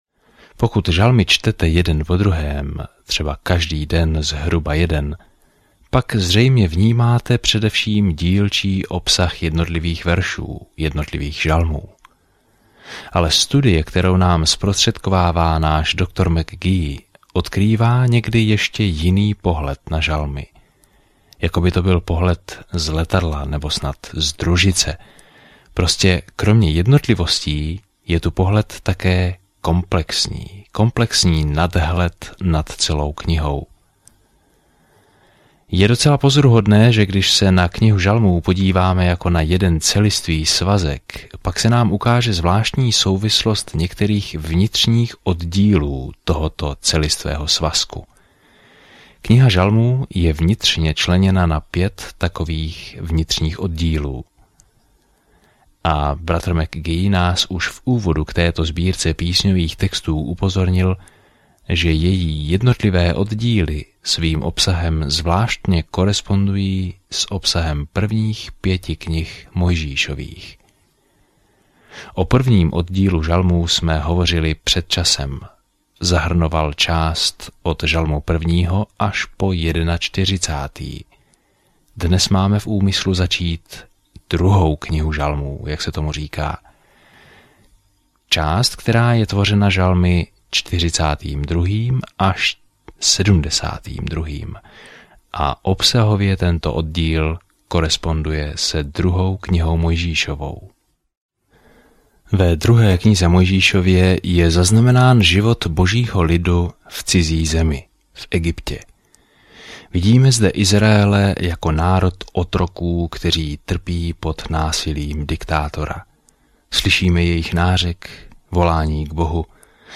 Písmo Žalmy 42 Den 29 Začít tento plán Den 31 O tomto plánu Žalmy nám dávají myšlenky a pocity z řady zkušeností s Bohem; pravděpodobně každý z nich původně zhudebnil. Denně procházejte žalmy, poslouchejte audiostudii a čtěte vybrané verše z Božího slova.